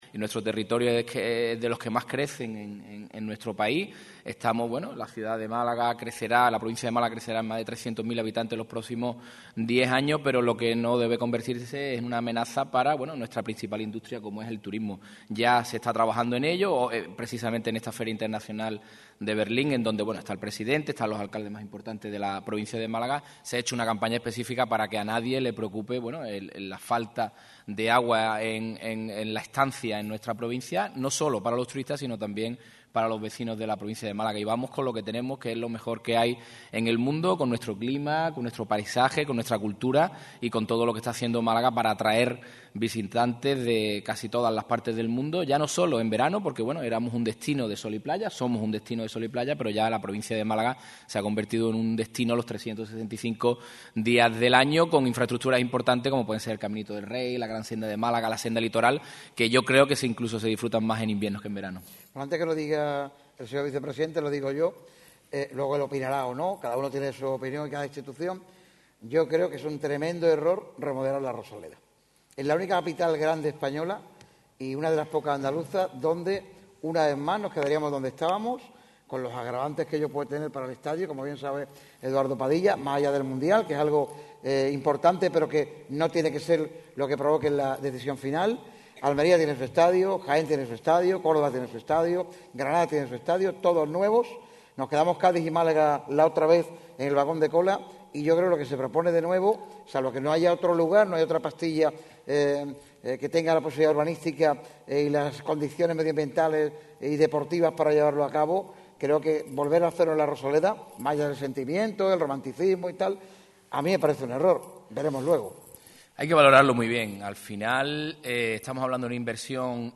El vicepresidente de la Diputación de Málaga deja claro en Radio MARCA Málaga como avanaza el proceso.
sobre el escenario del Auditorio Edgar Neville de la Diputación de Málaga con motivo de la celebración de la XXIV gala de aniversario de Radio MARCA Málaga realizada hoy miércoles 6 de marzo de 2024.